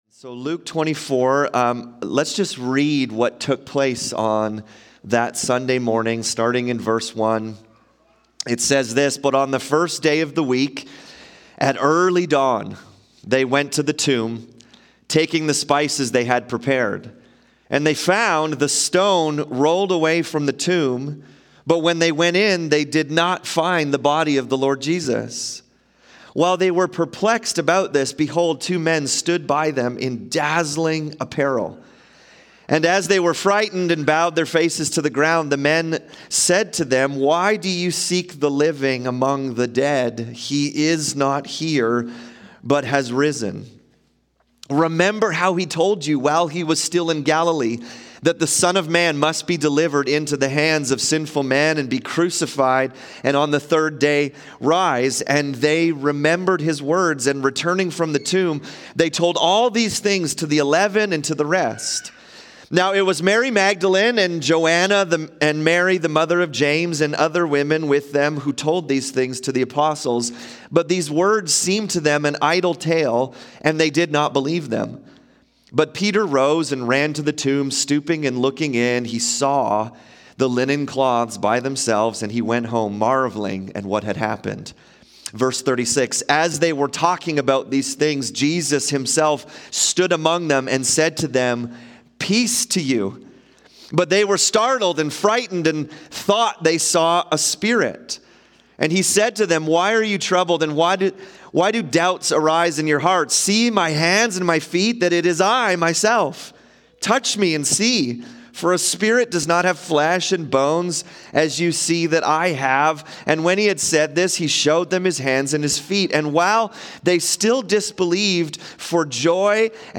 Subscribe via iTunes to our weekly Sermons